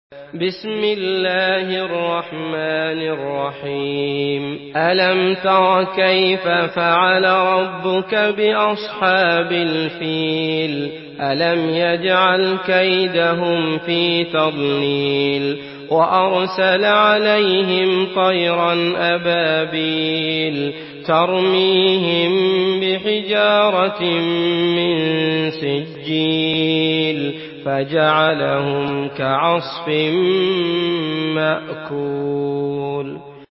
Surah Al-Fil MP3 in the Voice of Abdullah Al Matrood in Hafs Narration
Murattal Hafs An Asim